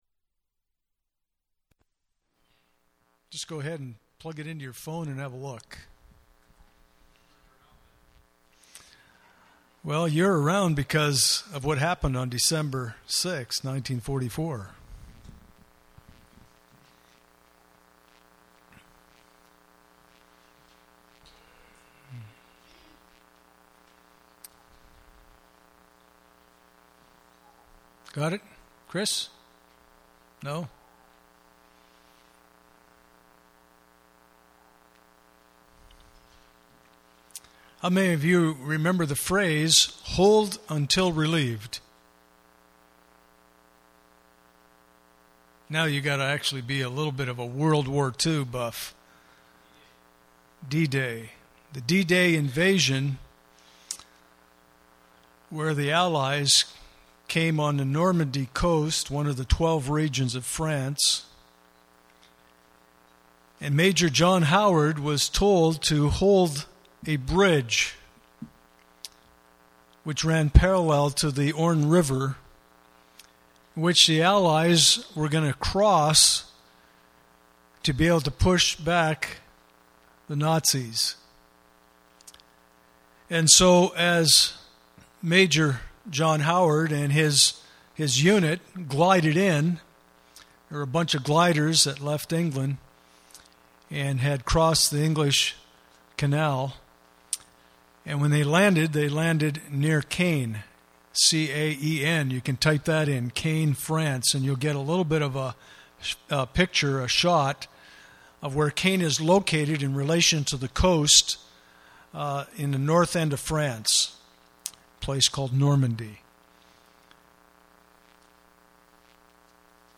Pastoral Epistles Passage: 1 Timothy 6:14-16 Service Type: Sunday Morning « Is Your Life Seizing Up?